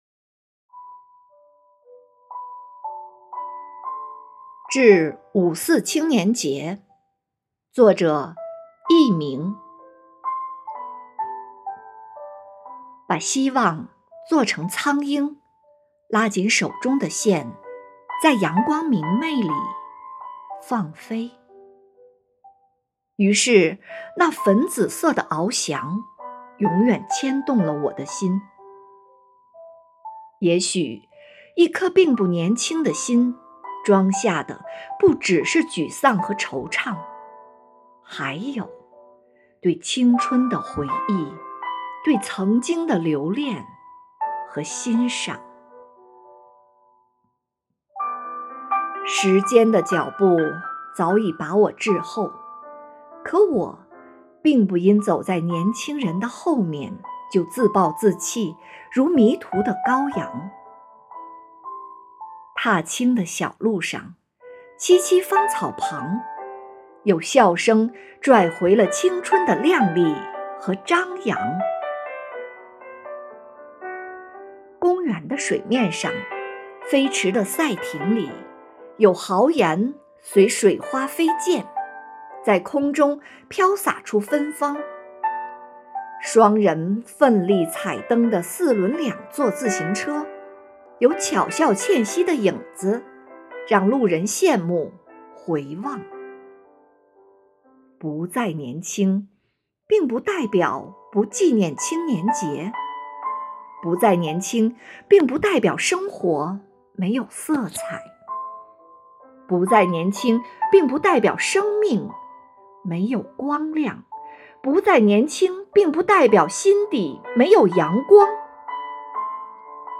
生活好课堂幸福志愿者中国钢研朗读服务（支）队第十一次云朗诵会在五月开启，声声朗诵、篇篇诗稿赞颂红五月，讴歌美好生活，吟诵美丽中国。
《致五四青年节》朗诵